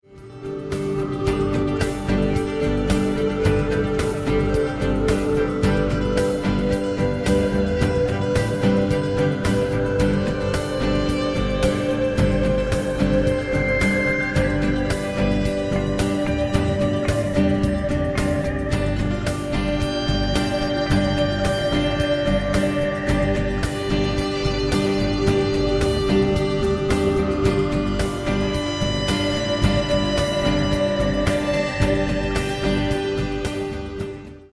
a broad spectrum of musical sounds